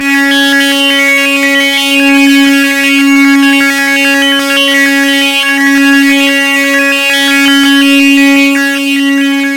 描述：通过Modular Sample从模拟合成器采样的单音。
Tag: CSharp5 MIDI音符-73 DSI-利 合成器 单票据 多重采样